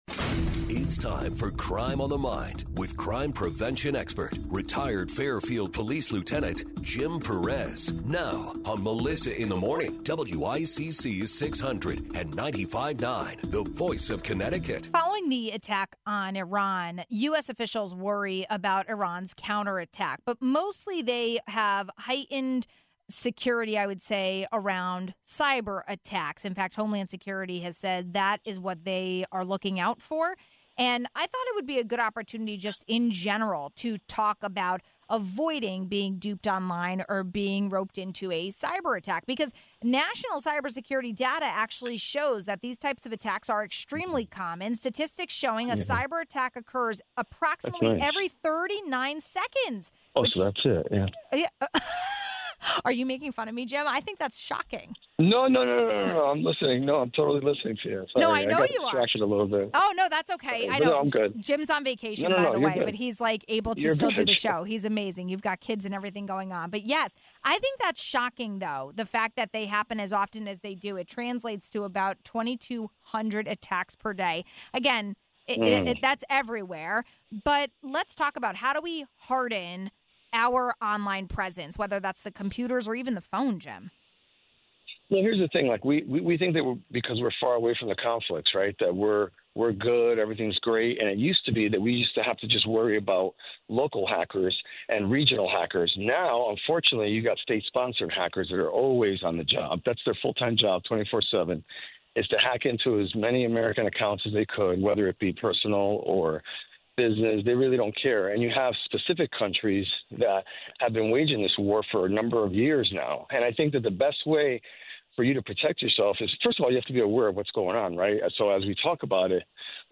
Following the attack on Iran, US officials worry about Iran’s counterattack; mostly with cyberattacks. We spoke with crime prevention expert